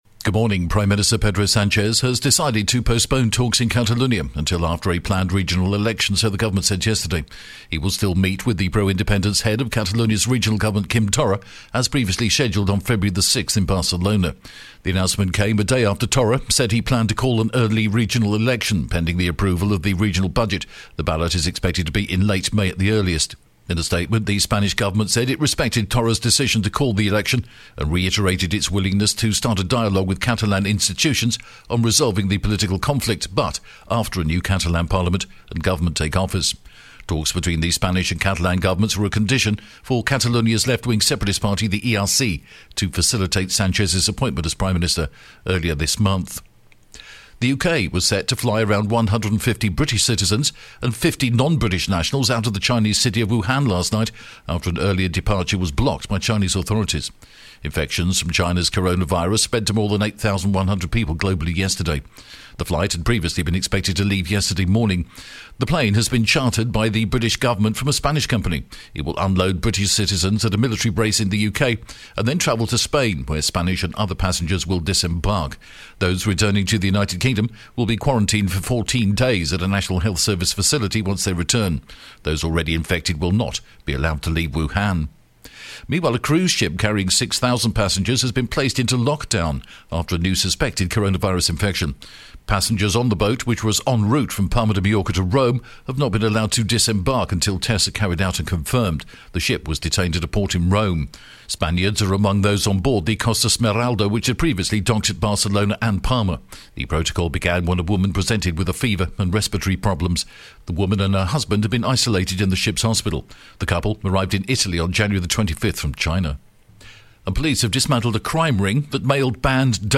The latest Spanish news headlines in English: January 31st